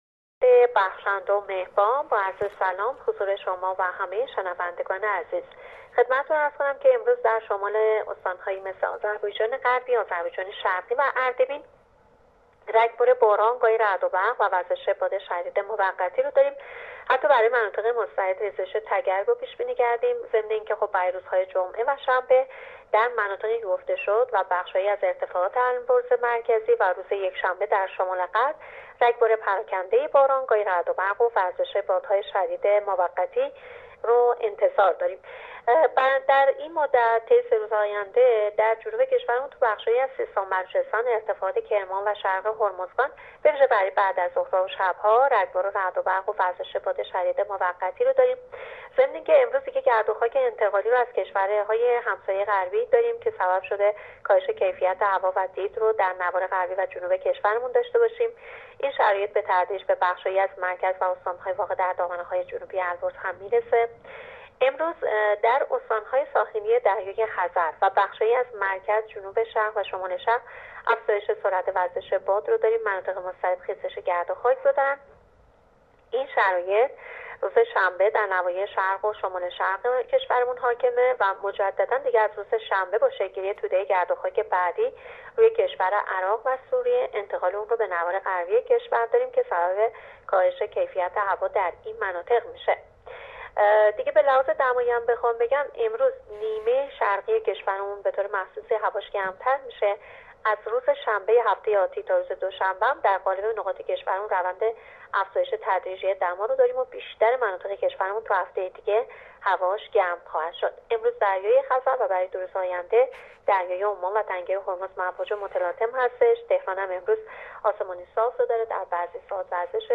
کارشناس سازمان هواشناسی در گفت‌وگو با رادیو اینترنتی پایگاه خبری وزارت راه‌ و شهرسازی، آخرین وضعیت آب‌وهوای کشور را اعلام کرد.
گزارش رادیو اینترنتی پایگاه خبری از آخرین وضعیت آب‌وهوای دوم تیر ماه؛